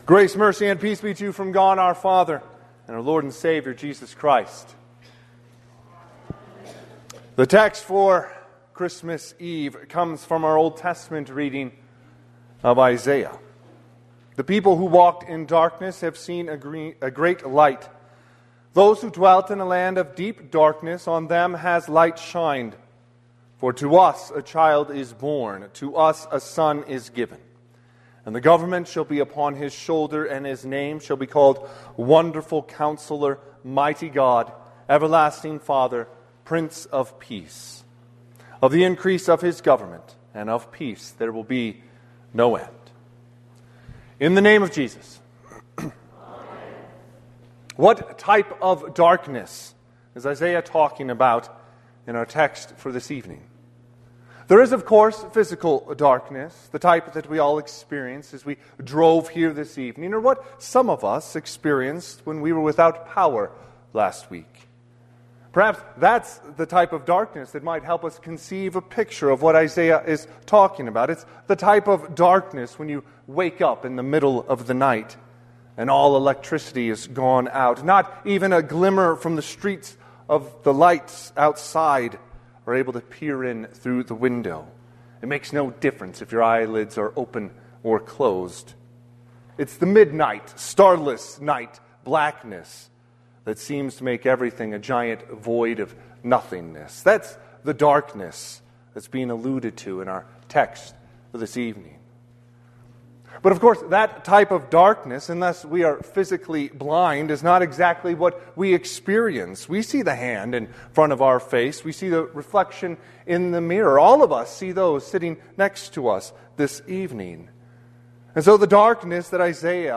Christmas Eve Service / 7PM